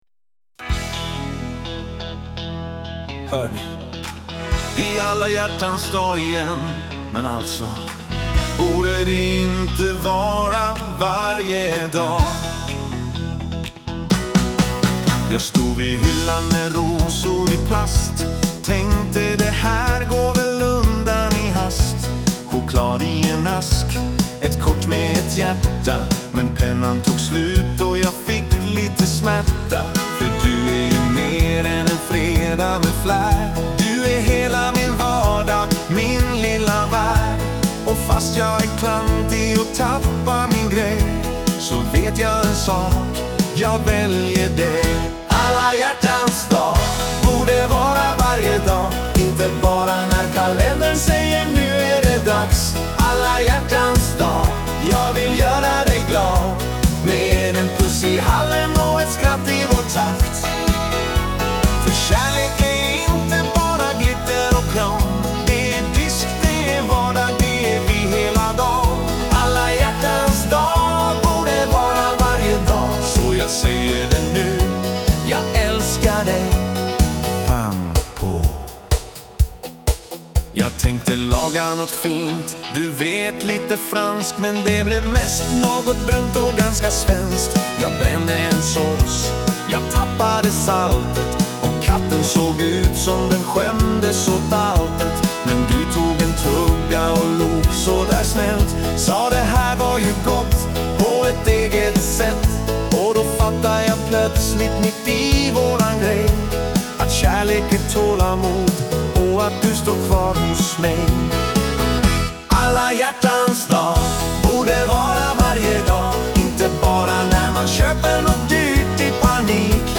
en varm, vardagsnära hyllning till kärleken.
svenskt dansband